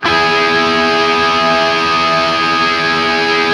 TRIAD E  L-R.wav